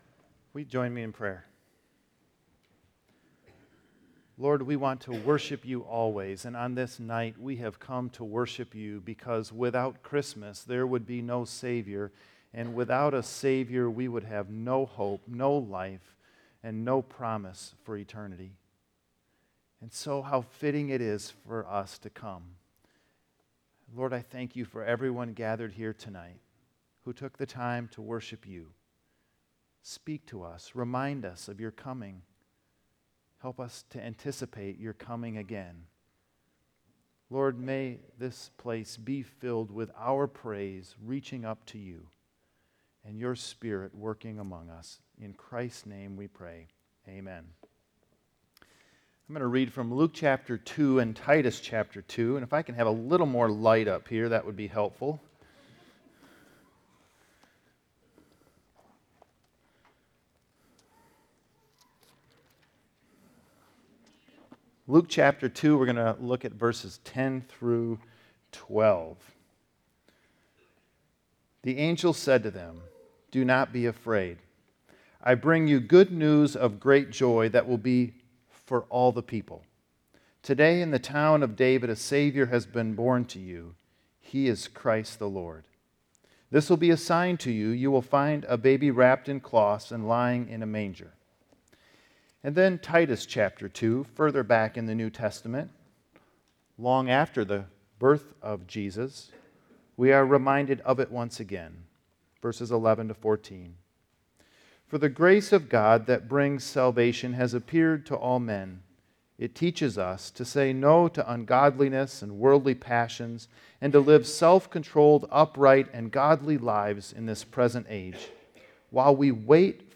Sermons - Woodhaven Reformed Church
Christmas eve 2017.mp3